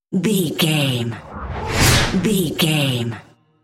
Whoosh fast trailer
Sound Effects
Fast paced
Fast
high tech
whoosh